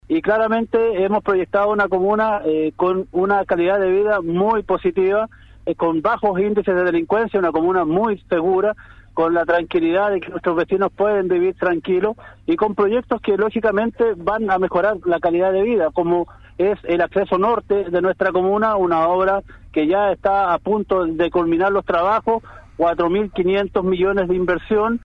En palabras del alcalde Juan Soto, San Pablo destaca por ser una comuna que presenta altos niveles de seguridad, con bajos índices de delincuencia, lo que es valorado por sus habitantes.